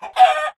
chickenhurt1